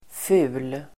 Uttal: [fu:l]